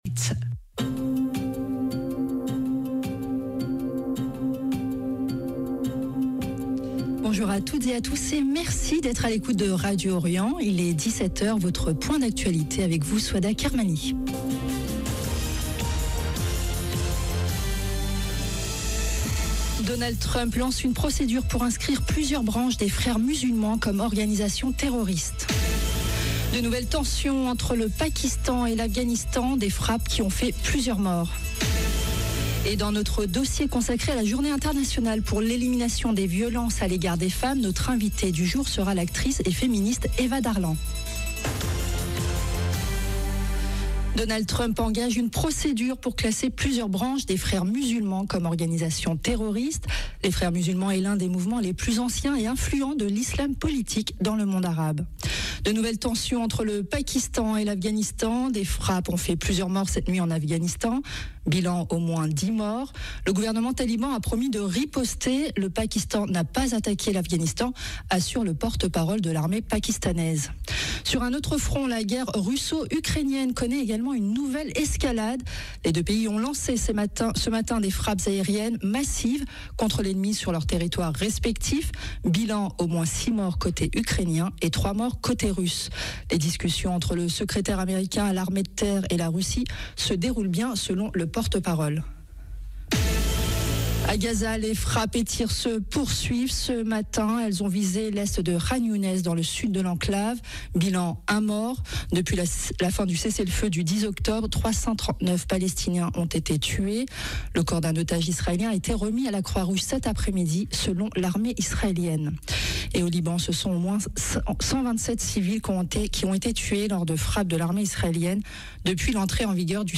JOURNAL DE 17H Donald Trump lance une procédure pour inscrire plusieurs branches des Frères musulmans comme organisation terroriste. De nouvelles tensions entre le Pakistan et l’Afghanistan.
Et dans notre dossier consacré à la Journée internationale pour l’élimination des violences à l’égard des femmes, notre invité du jour sera l’actrice et féministe Eva Darlan. 0:00 8 min 8 sec